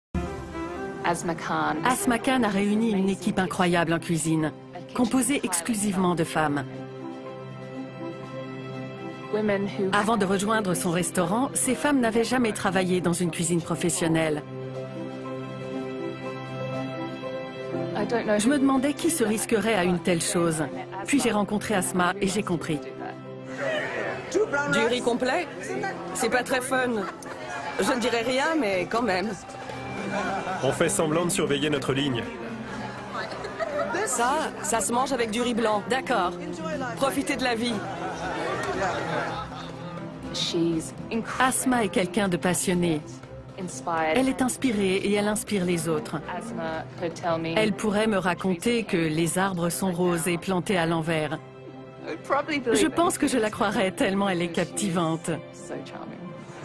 Voice over Chef's table
Voix off